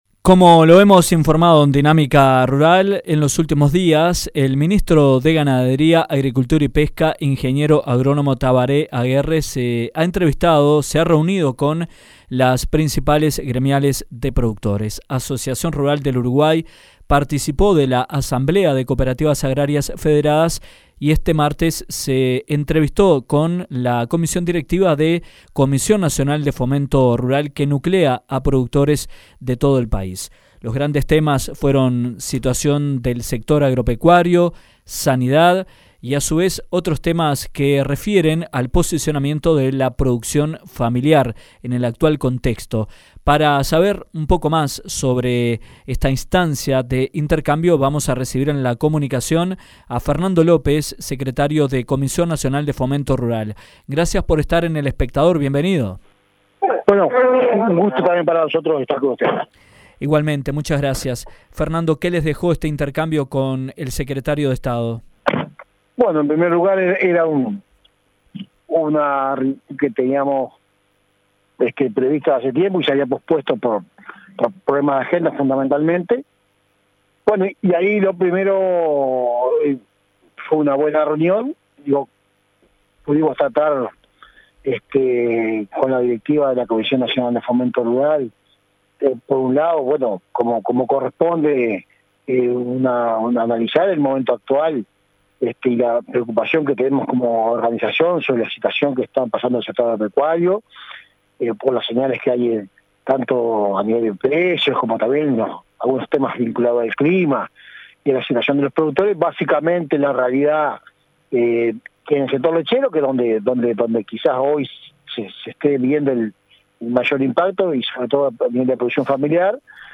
En entrevista con Dinámica Rural, el ministro de Ganadería Agricultura y Pesca, Ing. Agr. Tabaré Aguerre, dijo que según la información del BCU el endeudamiento agropecuario actualmente, "es similar al de un  año atrás y es prácticamente el mismo que en junio de 2014";,  argumentando  que "no habían problemas de precios";.